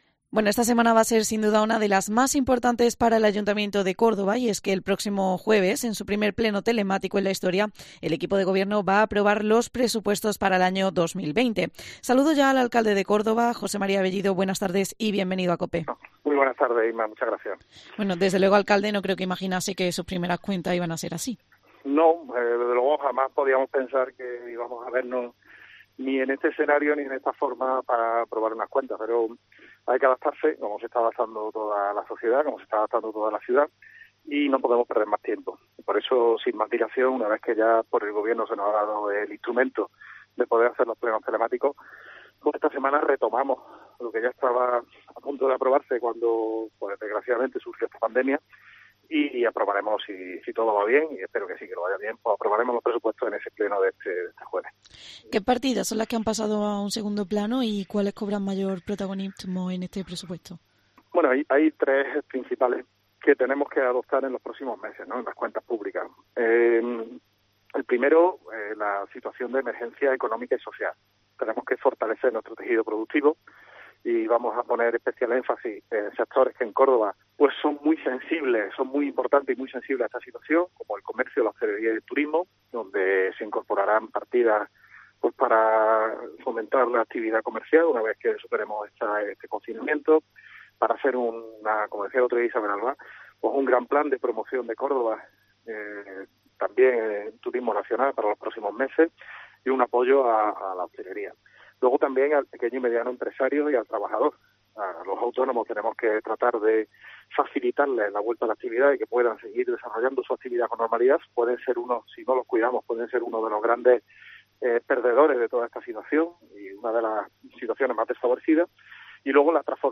El alcalde ha señalado en los micrófonos de COPE que no es necesario contar con el voto a favor de PSOEIzquierda Unida y Podemos, porque el apoyo de Vox es suficiente para la aprobación de las cuentas municipales, pero “no queremos aprobar las cuentas, sino dar un paso más allá”.